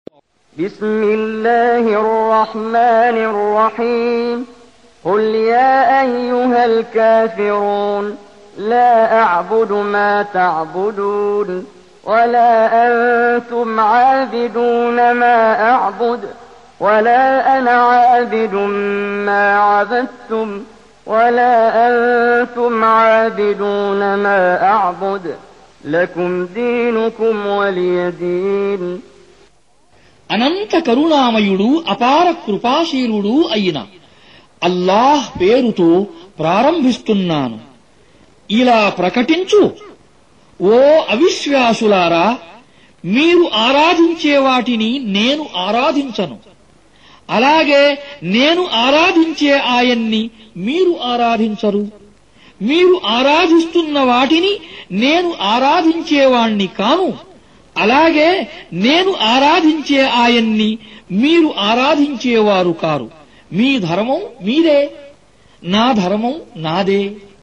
Surah Repeating تكرار السورة Download Surah حمّل السورة Reciting Mutarjamah Translation Audio for 109. Surah Al-K�fir�n سورة الكافرون N.B *Surah Includes Al-Basmalah Reciters Sequents تتابع التلاوات Reciters Repeats تكرار التلاوات